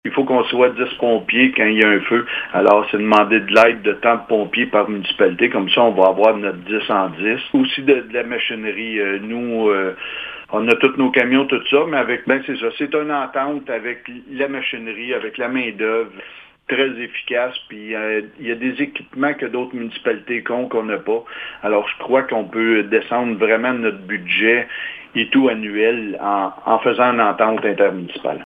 Lors de la dernière assemblée municipale de la Municipalité de Bouchette, une résolution a été adoptée relativement à une entente intermunicipale avec les municipalités de Blue Sea et de Messines concernant le Service de sécurité incendie. Le maire de Bouchette, Steve Lefebvre, nous explique pourquoi cette entente est nécessaire dans le but d’optimiser le service et le déploiement des effectifs sur le terrain :